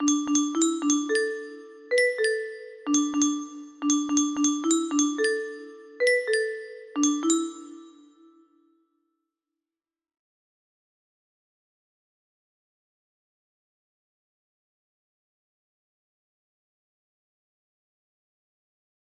FINISH THIS LATER music box melody
Full range 60